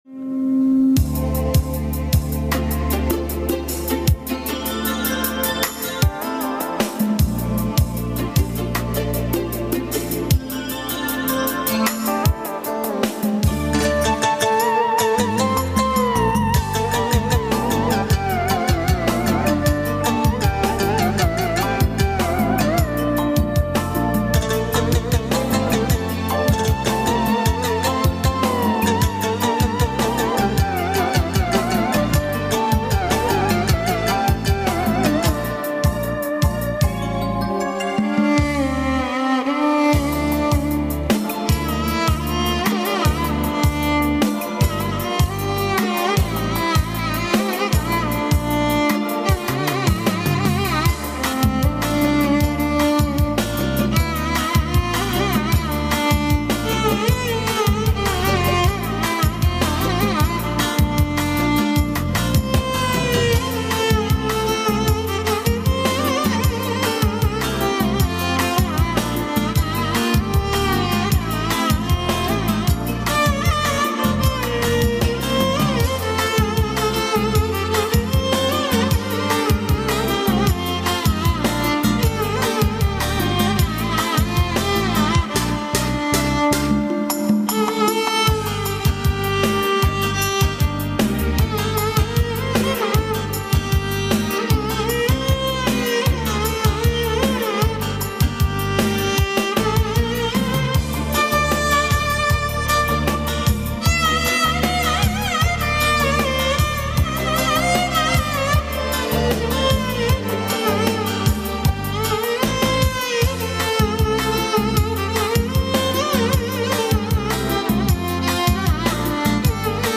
эстрадные пьесы
Запись была сделана мною с местного интернет радио.